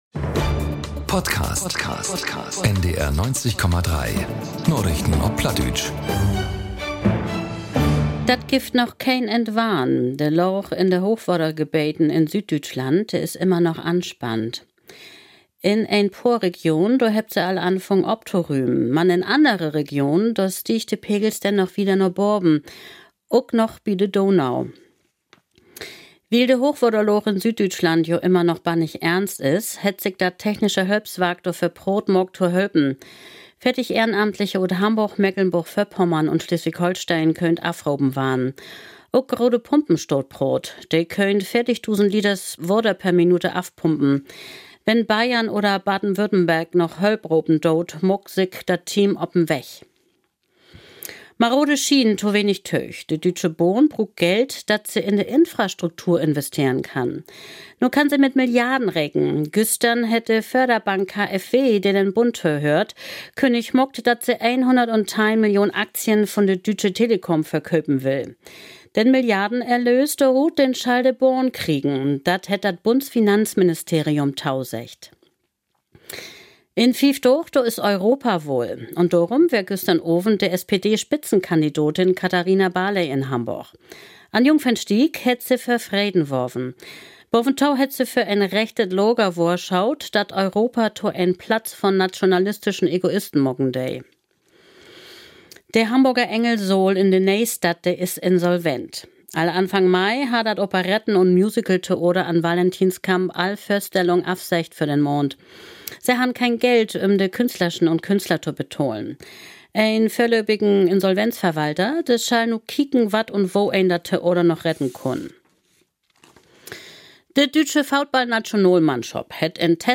Nachrichten - 04.06.2024